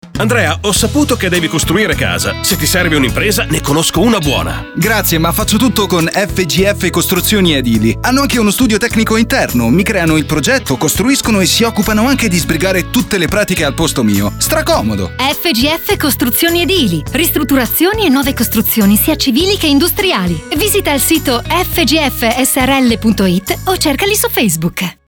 Estratto radiofonico
FGF-COSTRUZIONI-SPOT.mp3